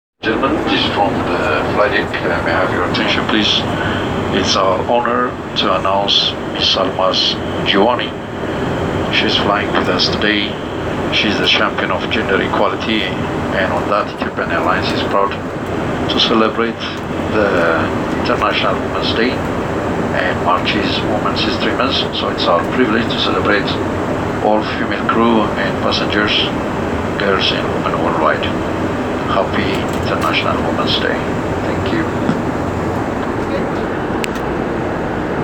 in-flight announcement